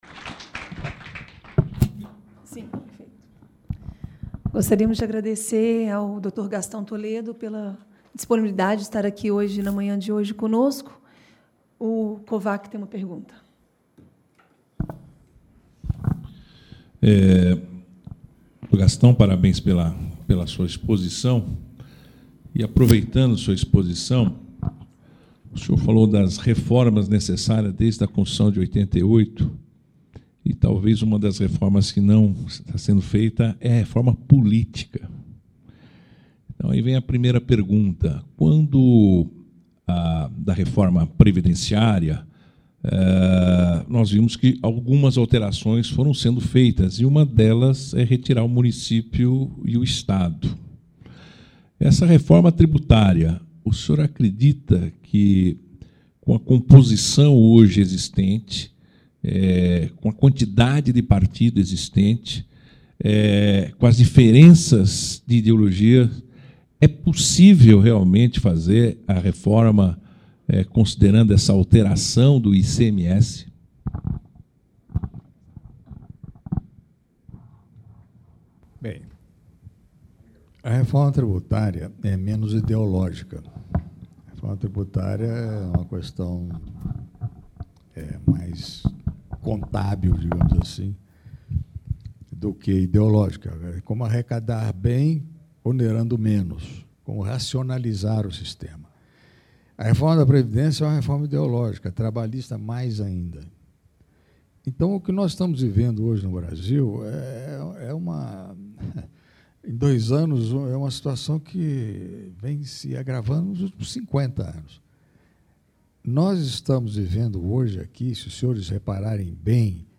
Os efeitos das reformas estruturais do governo na educação superior brasileira (Debate)